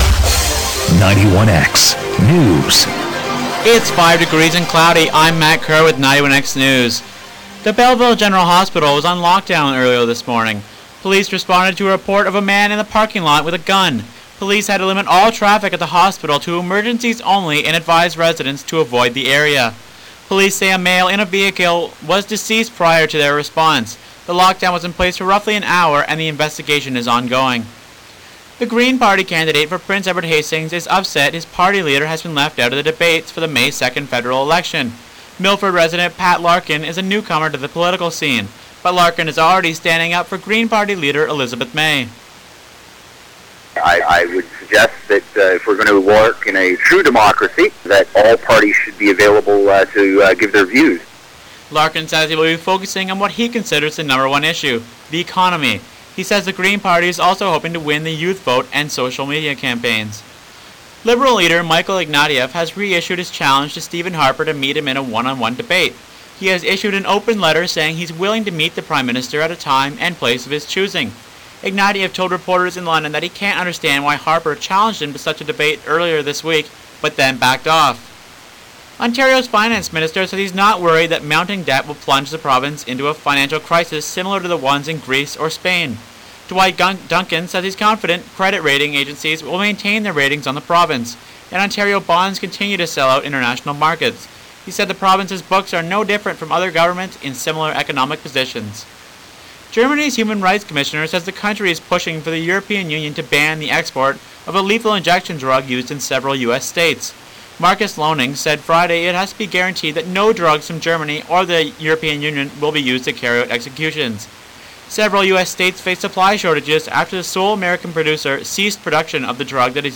91x News April 1, 2011 3 p.m